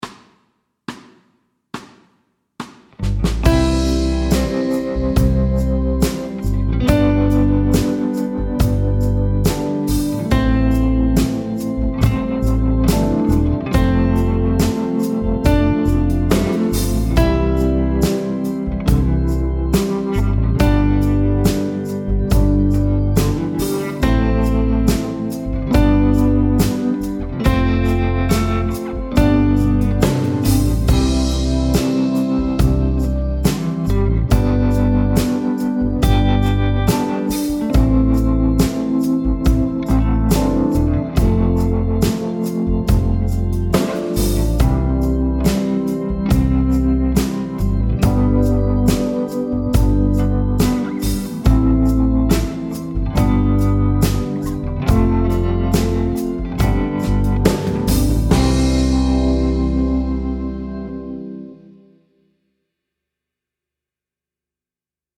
Medium C instr (demo)
Rytmeværdier: 1/1- og 1/2 noder.